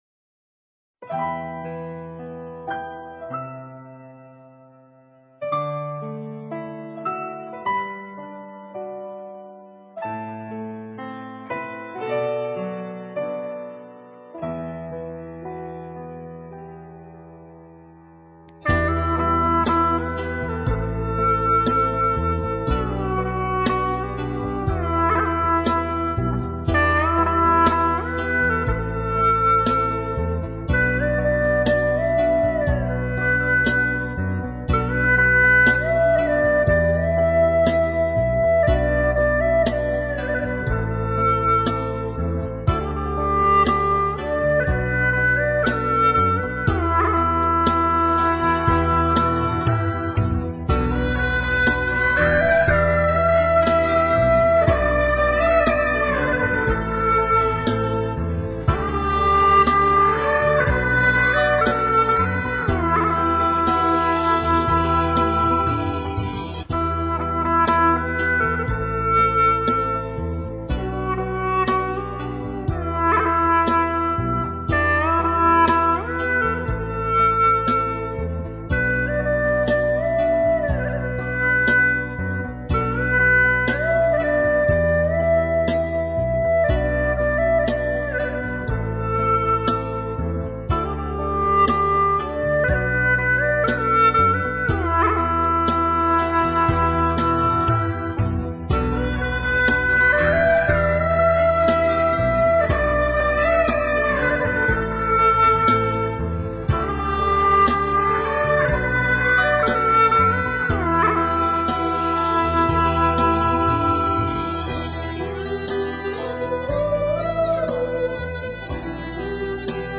调式 : G 曲类 : 流行